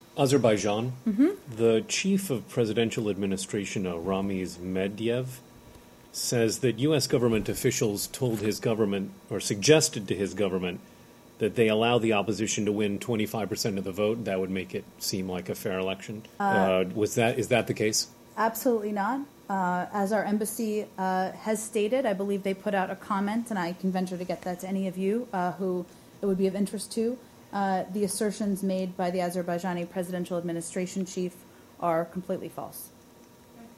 Bir qədər əvvəl ABŞ Dövlət Departamentinin gündəlik brifinqi keçirilib.